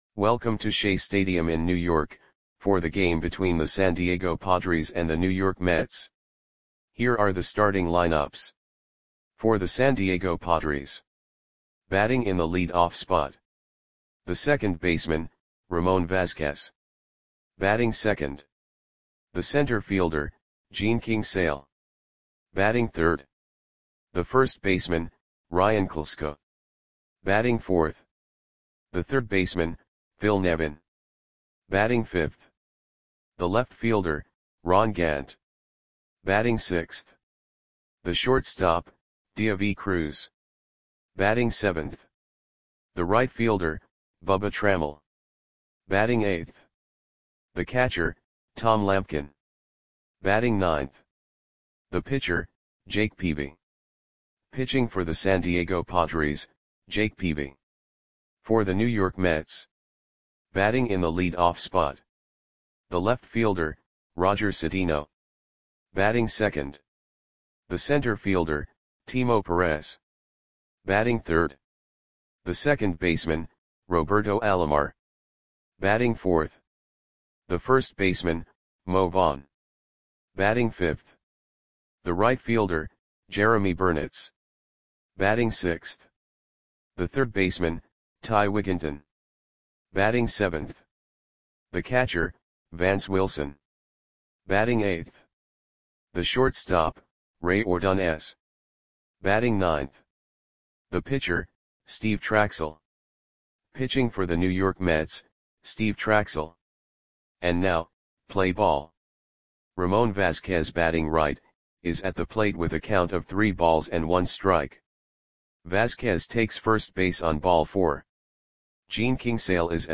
Audio Play-by-Play for New York Mets on August 13, 2002
Click the button below to listen to the audio play-by-play.